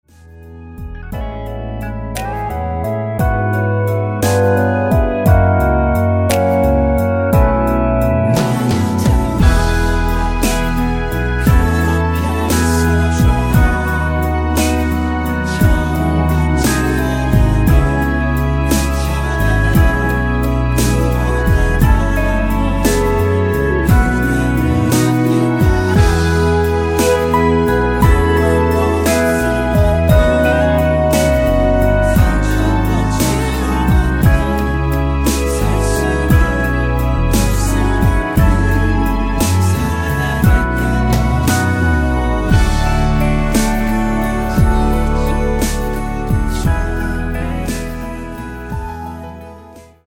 엔딩이 페이드 아웃이라 엔딩을 만들어 놓았습니다.
코러스 포함된 버젼 입니다.(미리듣기 참조)
앞부분30초, 뒷부분30초씩 편집해서 올려 드리고 있습니다.
중간에 음이 끈어지고 다시 나오는 이유는